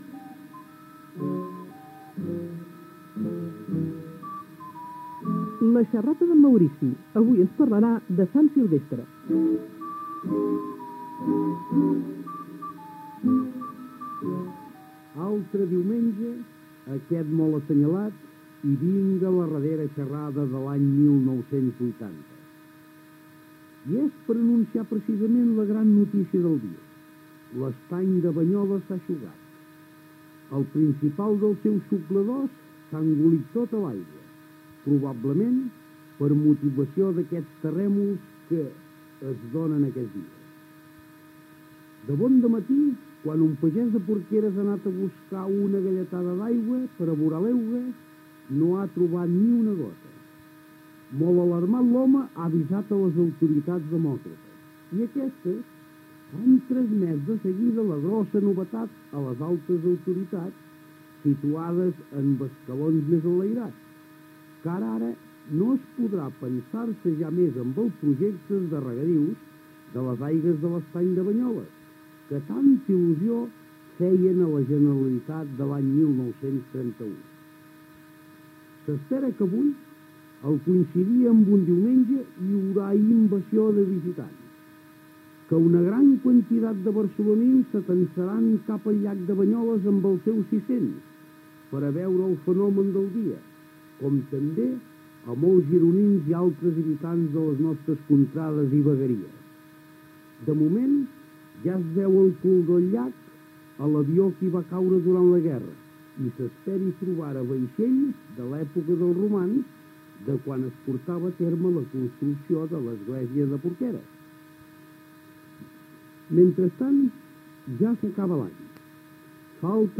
Careta del programa, publicitat, reflexió sobre la fi de l'any, tema musical, indicatiu, publicitat, l'obra social de la Caixa Rural Provincial (edició d'un llibre sobre la pagesia).
Entreteniment